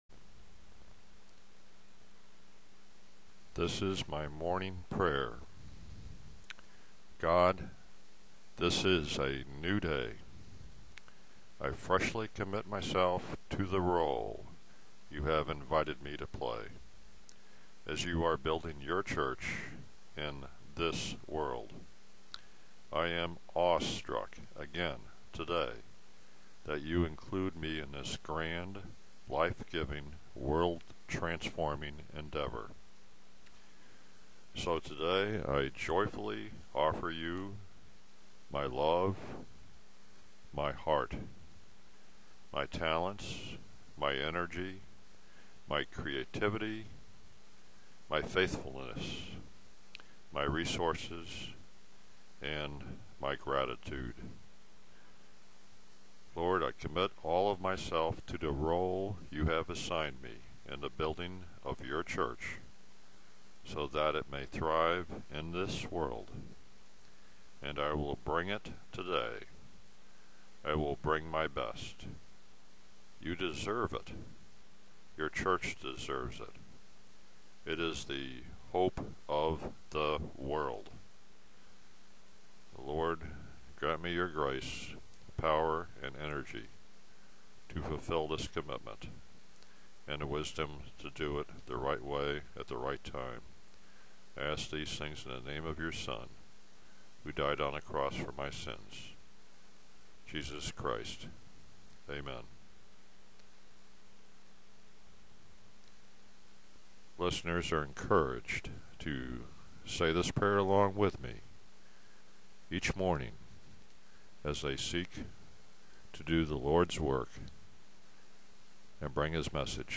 MY MORNING PRAYER.
Up_Reach_Morning_Prayer_cTc.tv.wma